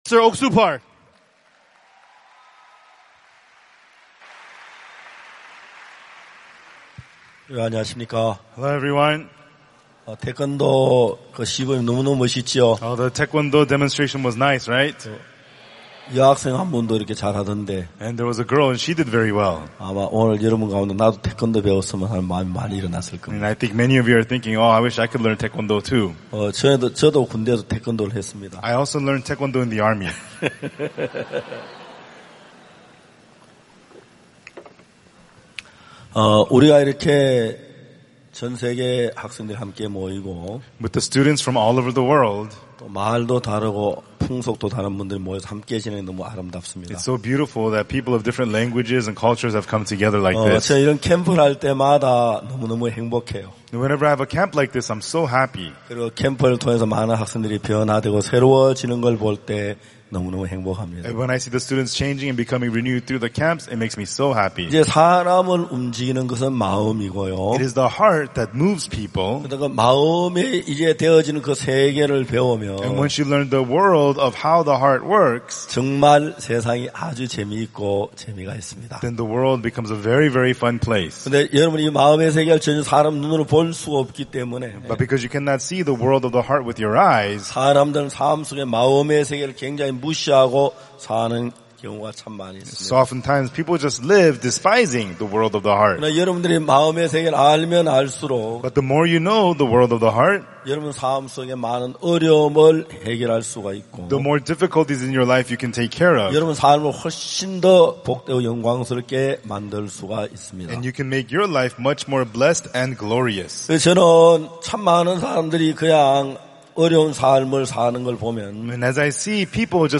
IYF 월드캠프의 주요 프로그램인 마인드 강연. 진정한 삶의 의미를 찾지 못하고 스스로에게 갇혀 방황하는 청소년들에게 어디에서도 배울 수 없는 마음의 세계, 그리고 다른 사람들과 마음을 나누는 방법을 가르친다.